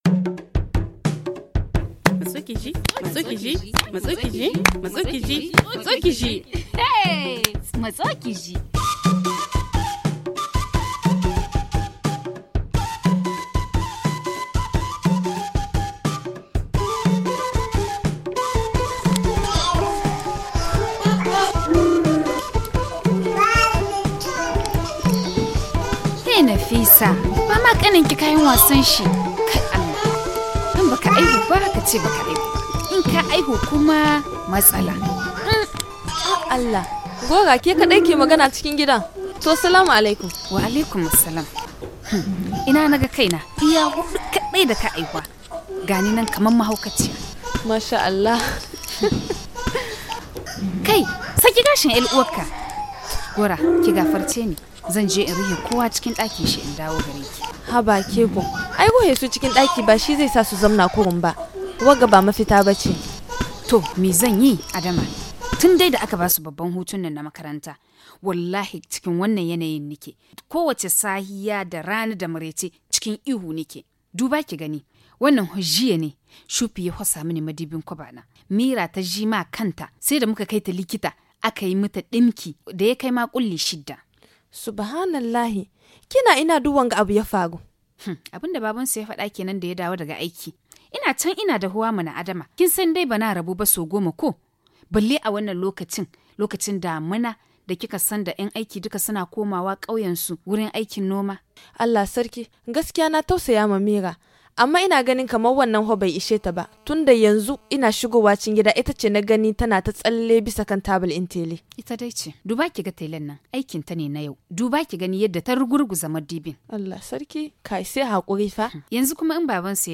Suivons cette conservation entre ces deux amies.